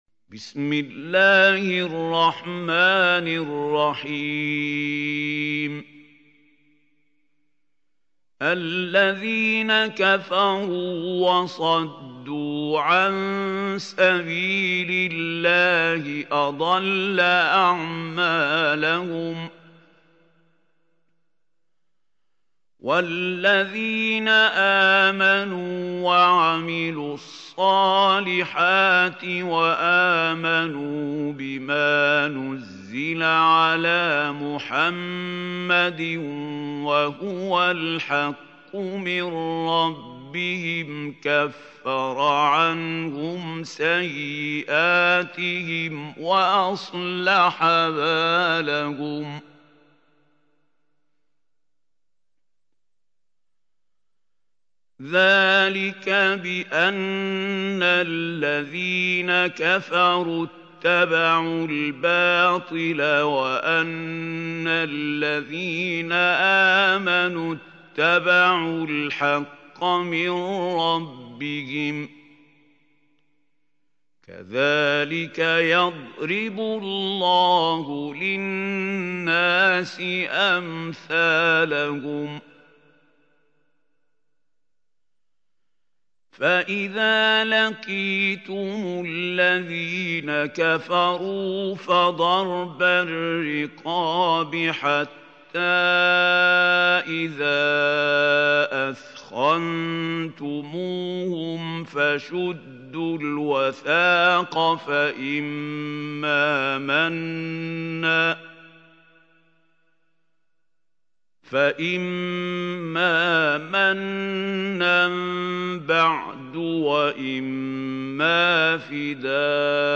سورة محمد | القارئ محمود خليل الحصري